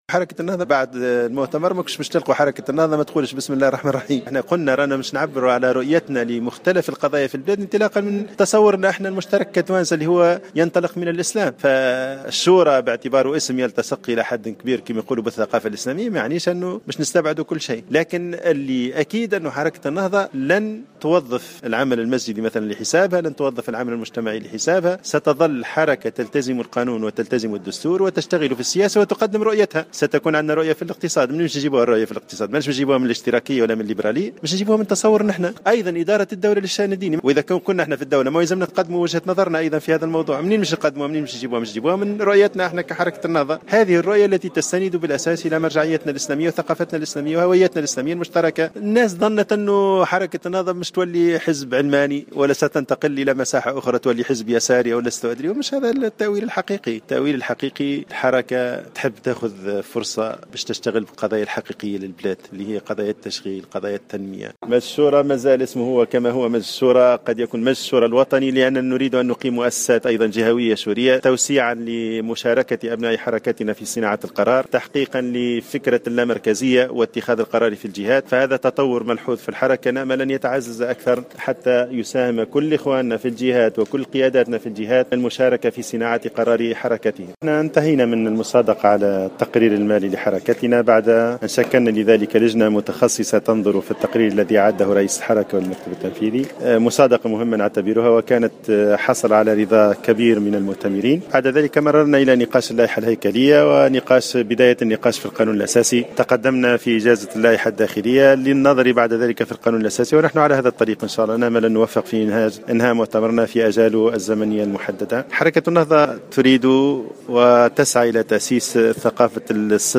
أكد رئيس مجلس شورى حركة النهضة فتحي العيادي اليوم الأحد 22 ماي 2016 على هامش اليوم الثالث والأخير من أشغال المؤتمر العاشر للحركة المنعقد بالحمامات أن الفصل بين الدعوي والسياسي لا يعني أن الحركة ستتخلى عن مجلس الشورى الوطني.